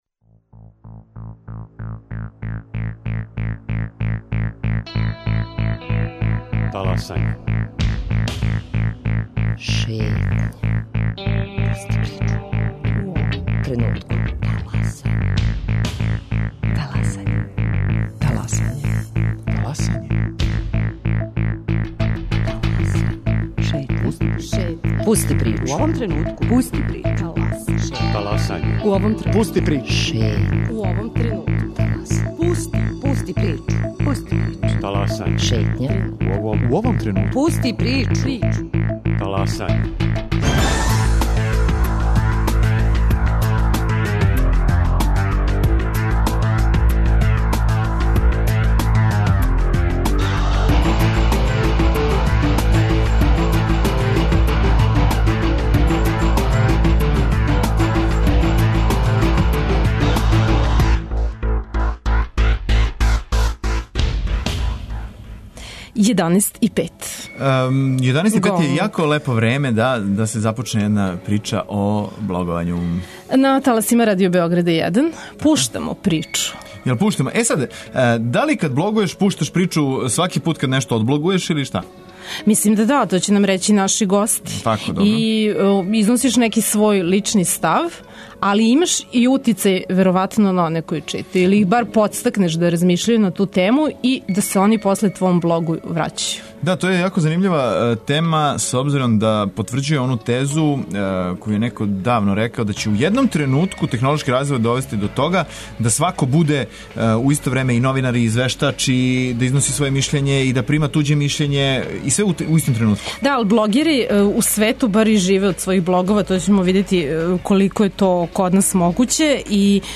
Укључићемо и неколико најчитанијих и најпризнатијих српских блогера.